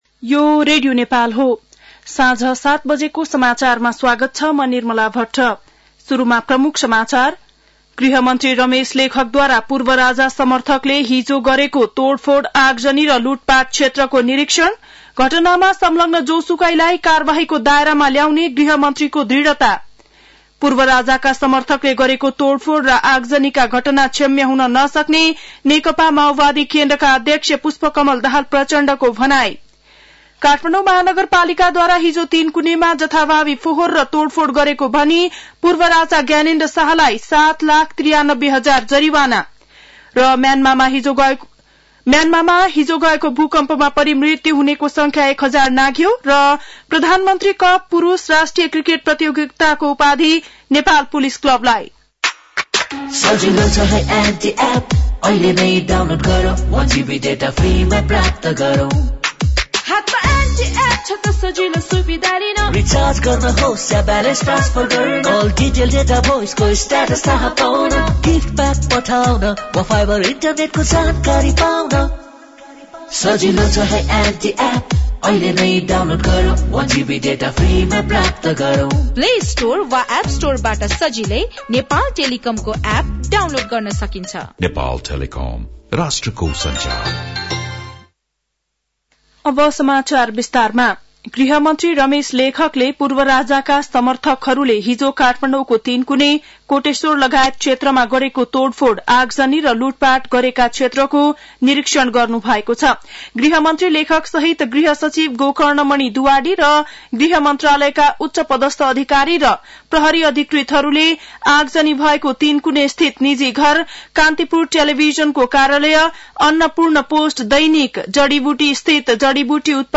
बेलुकी ९ बजेको नेपाली समाचार : १६ चैत , २०८१